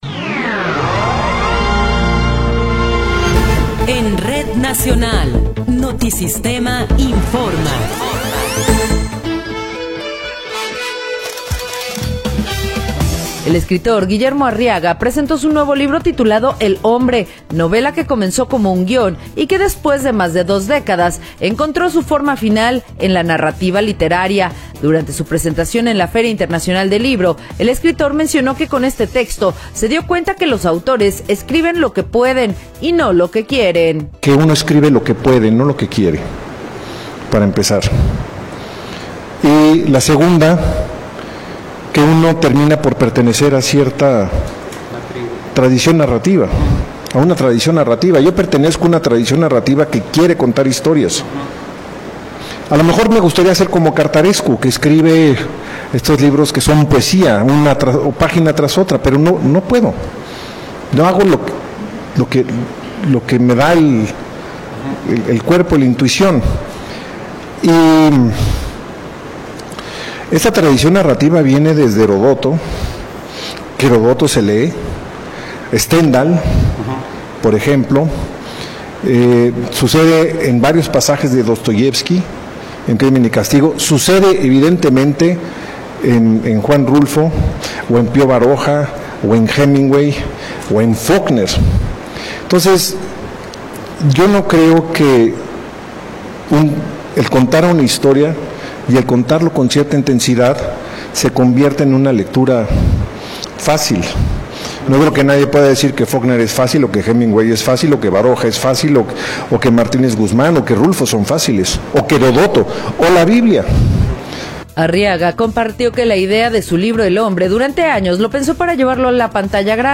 Noticiero 10 hrs. – 11 de Enero de 2026
Resumen informativo Notisistema, la mejor y más completa información cada hora en la hora.